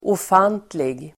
Uttal: [of'an:tlig]